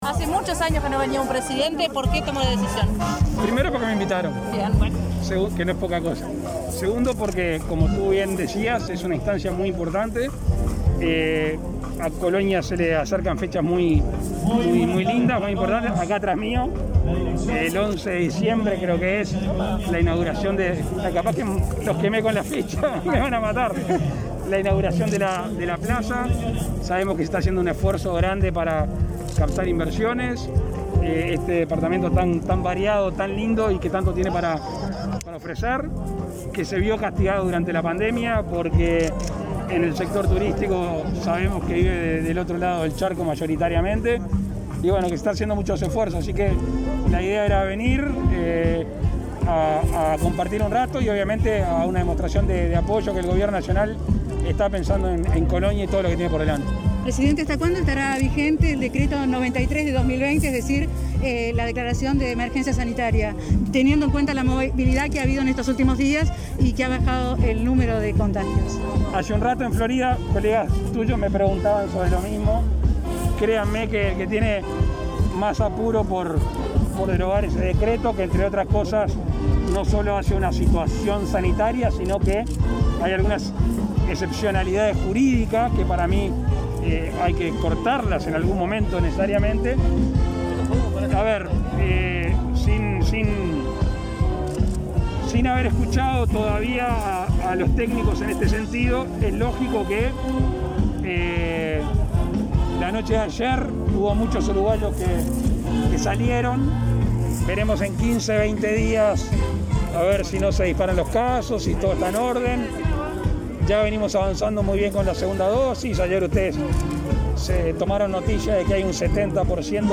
Declaraciones a la prensa del presidente Luis Lacalle Pou en Colonia
Declaraciones a la prensa del presidente Luis Lacalle Pou en Colonia 25/08/2021 Compartir Facebook X Copiar enlace WhatsApp LinkedIn El presidente Luis Lacalle Pou asistió este miércoles 25 a la entrega de premios del clásico hípico Presidente de la República y, luego, dialogó con la prensa.